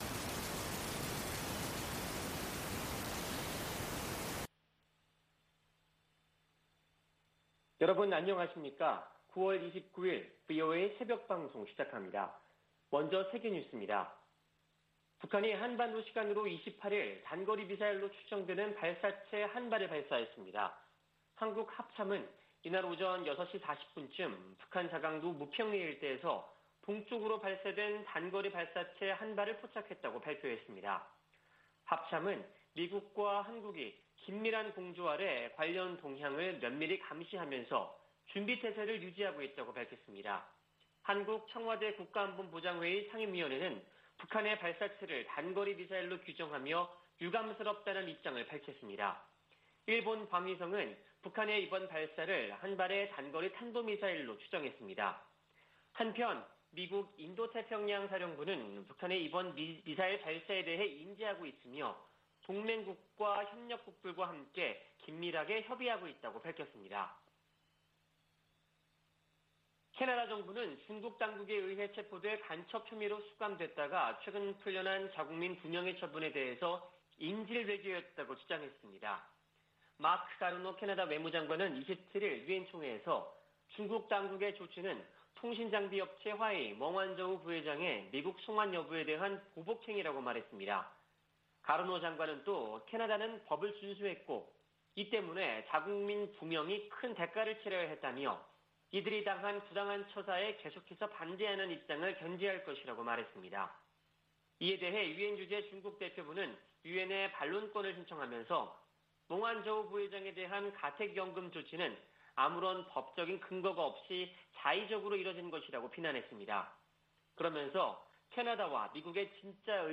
VOA 한국어 '출발 뉴스 쇼', 2021년 9월 29일 방송입니다.